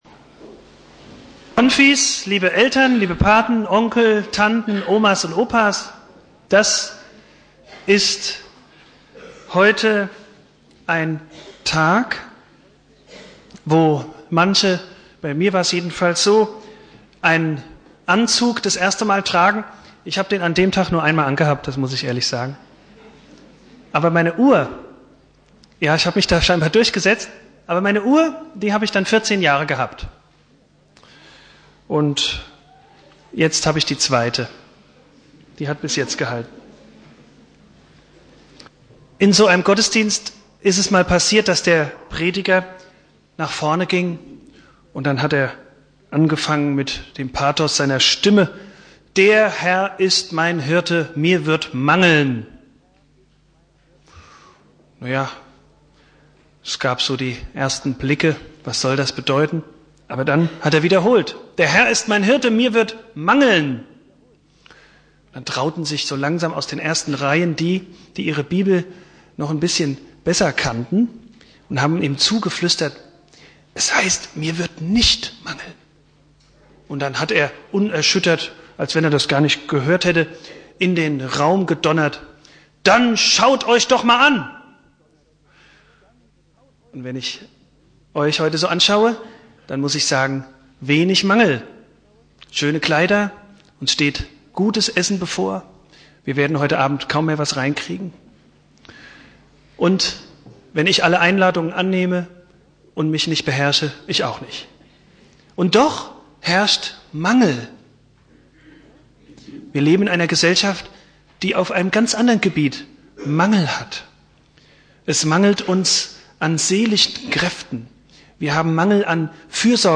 Predigt
deinen Weg" (Konfirmation Hausen) Bibeltext